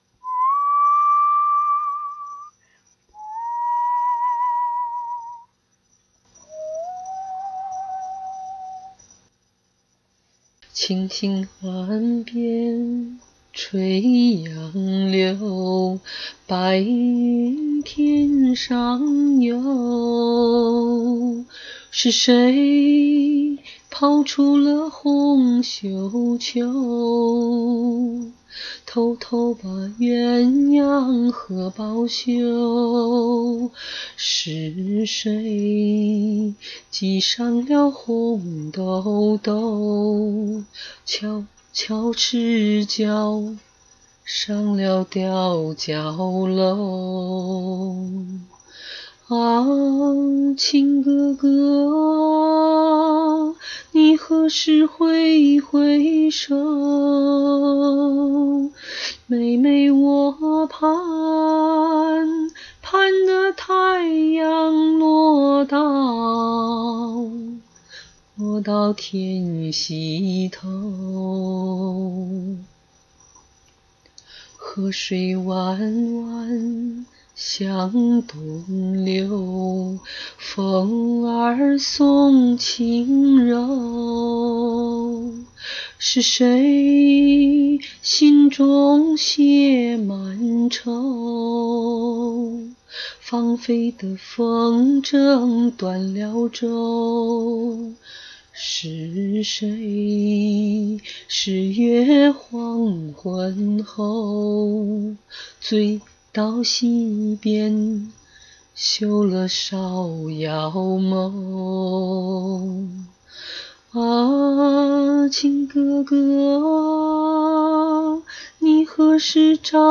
就山寨了一点傣歌风格
我继续哼歌，没有时间学做伴奏，见谅。